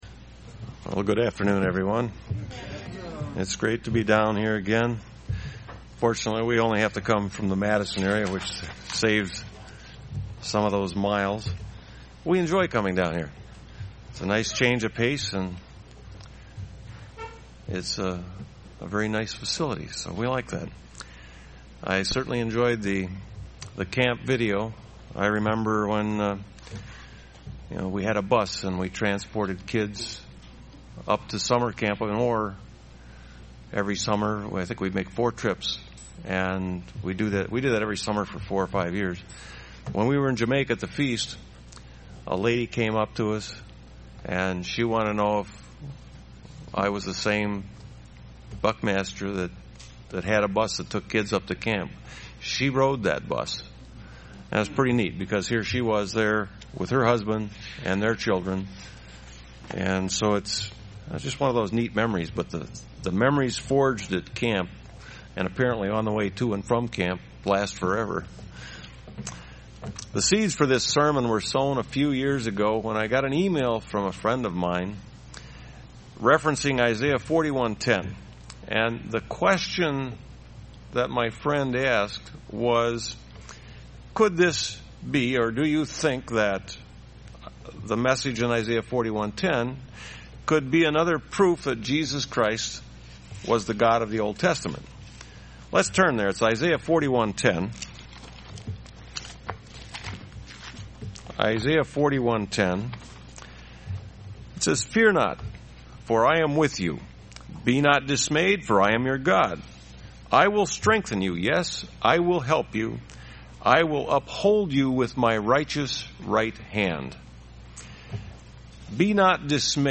Print What is meant by the phrase "The Right Hand of God" in Isaiah:41:10 UCG Sermon Studying the bible?